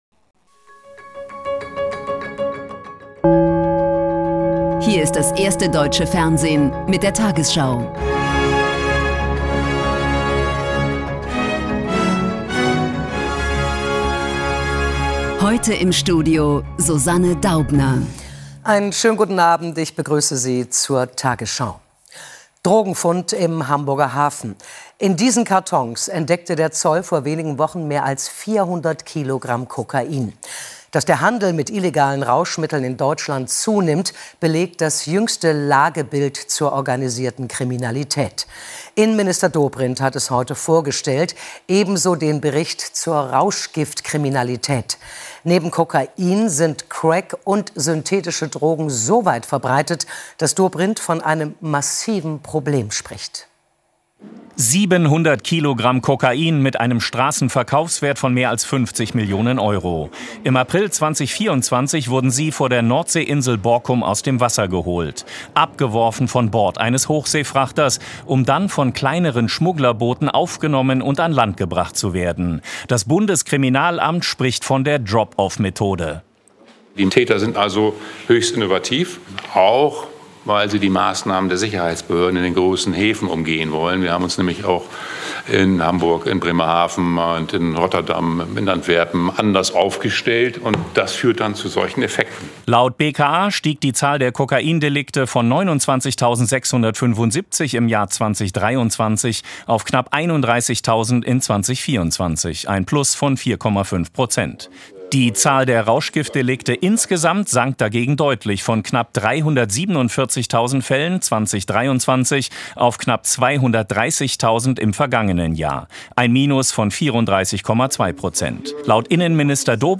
Tägliche Nachrichten